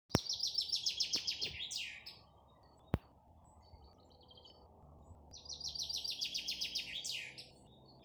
Birds -> Finches ->
Chaffinch, Fringilla coelebs
Ziņotāja saglabāts vietas nosaukumsCēsu nov, Ķempji
StatusSinging male in breeding season